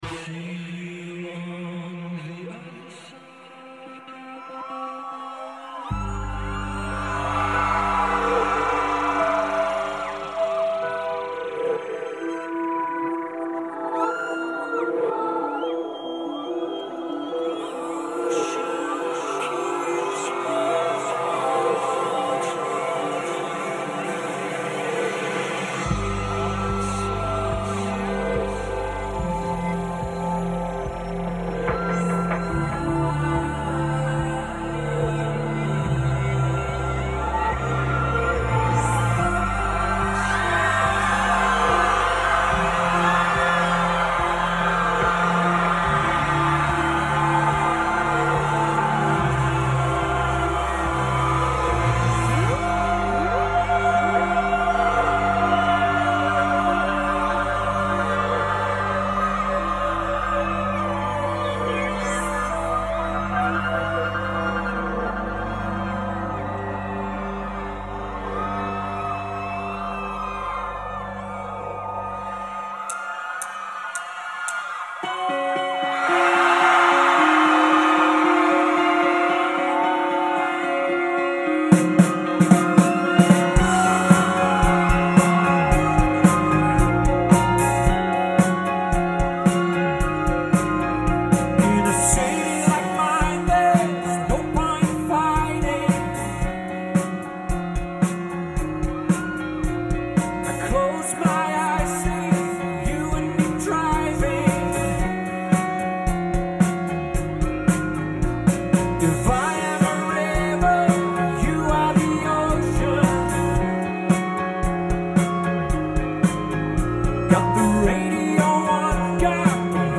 lead vocals, guitar, piano
piano, synthesisers, bass guitar, backing vocals
drums, percussion, backing vocals